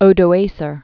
(ōdō-āsər) also O·do·va·car (-vākər) AD 434?-493.